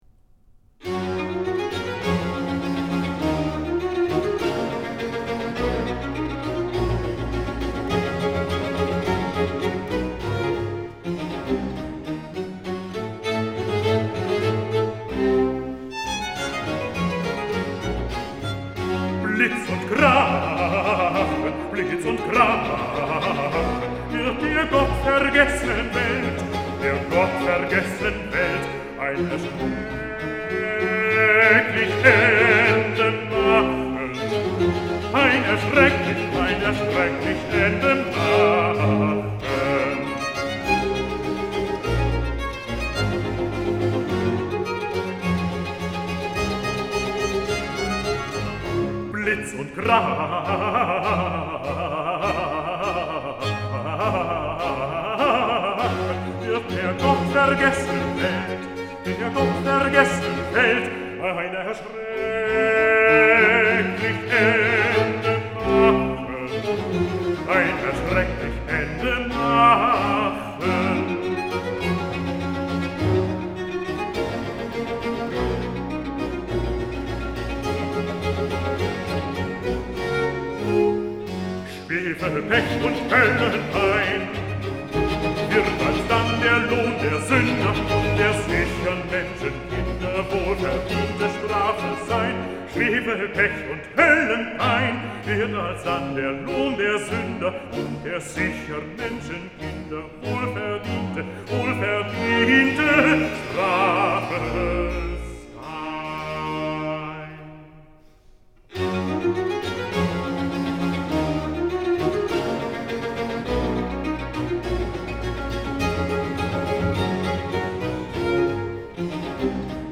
Bass-baritone
Aria